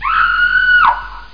pain_4.mp3